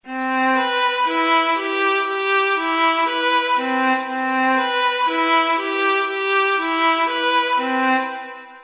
This illusion uses two melodies that sound jagged when played alone.
Sound: (09") – listen to the right ear jagged melody.